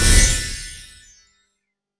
A magical spell being cast with energy gathering, release whoosh, and sparkling trail
spell-cast.mp3